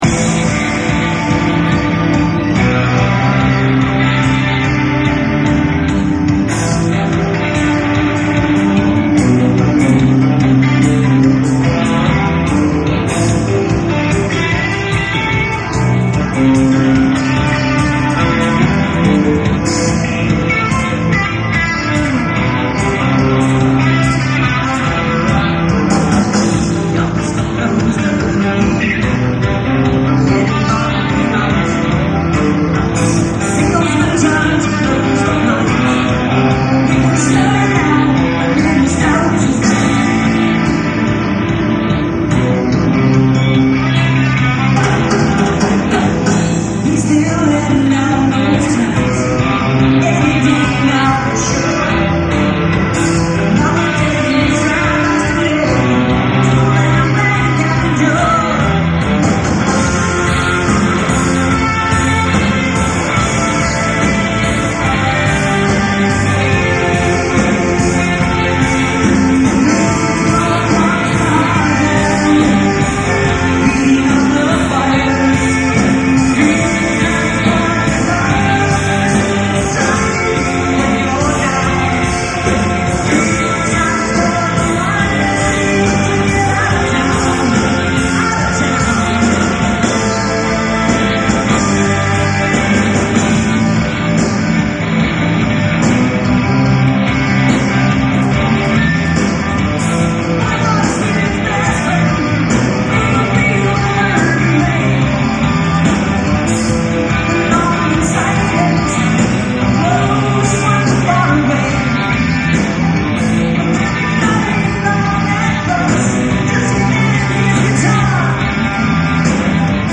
performed live prior to the studio recording